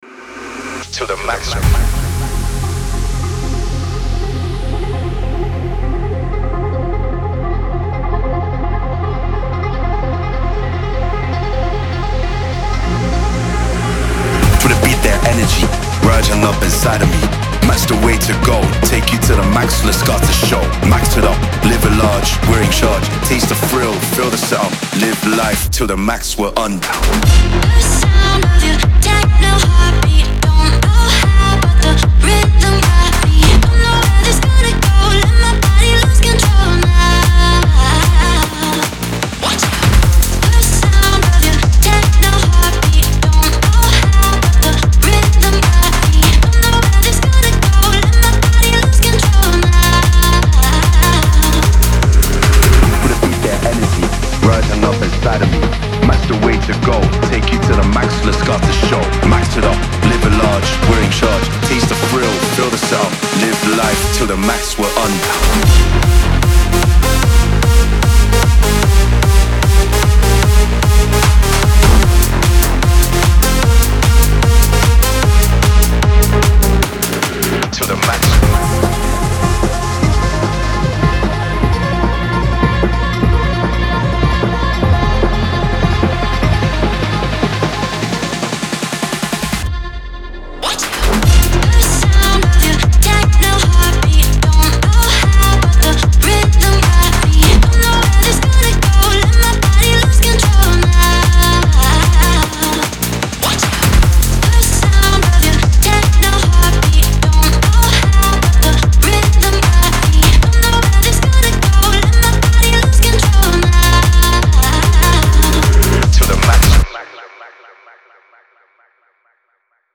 • Жанр: Dance